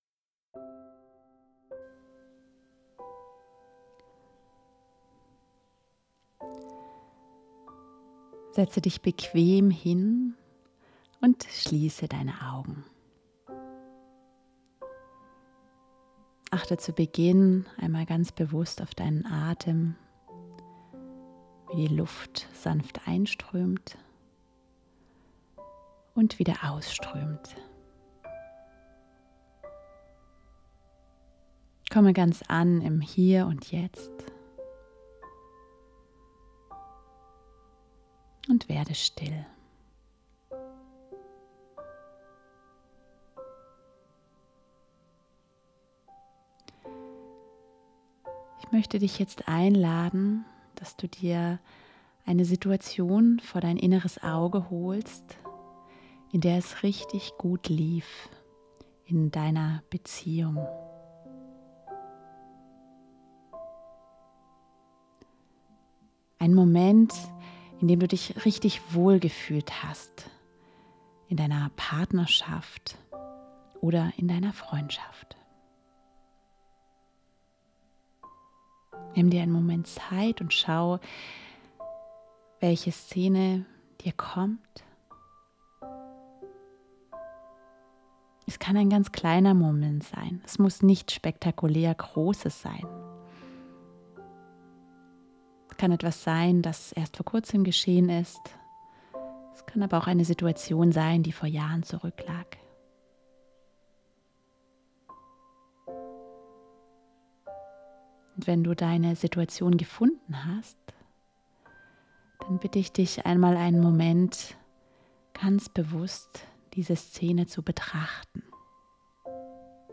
Gedankenreise: Emotionen